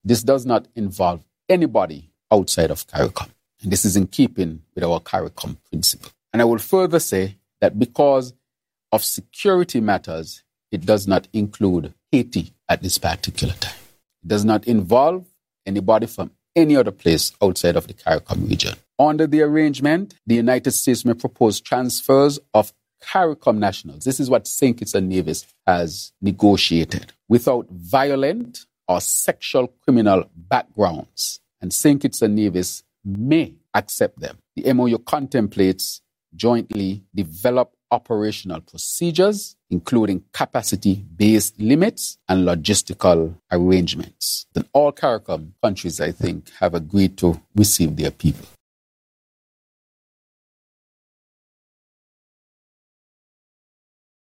Prime Minister, Dr. Terrance Drew, who is also Chairman of Caricom, explained this during his roundtable discussion with media on Jan. 8th.
PM Drew told the media the following.